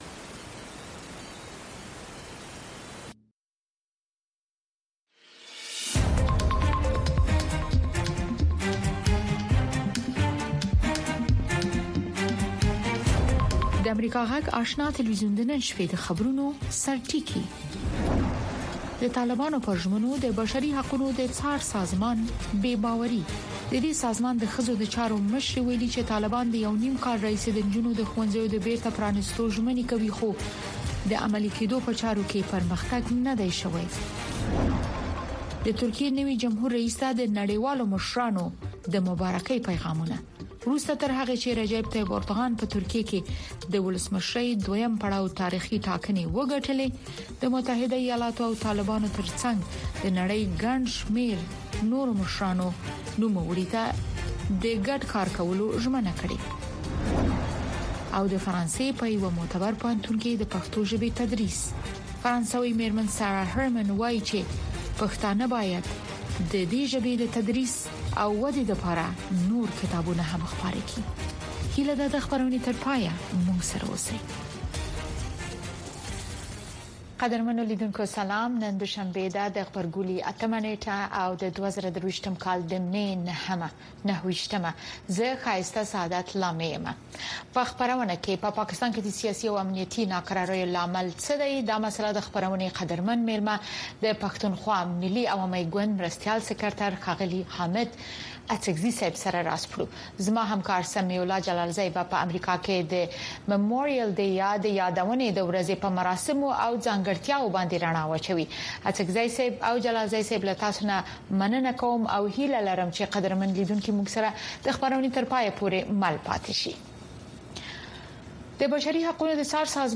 د اشنا په خبري خپرونو کې د شنبې څخه تر پنجشنبې پورې د افغانستان، سیمې او نړۍ تازه خبرونه، او د ټولې نړۍ څخه په زړه پورې او معلوماتي رپوټونه، د مسولینو او کارپوهانو مرکې، ستاسې غږ او نور مطالب د امریکاغږ راډیو، سپوږمکۍ او ډیجیټلي شبکو څخه لیدلی او اوریدلی شی.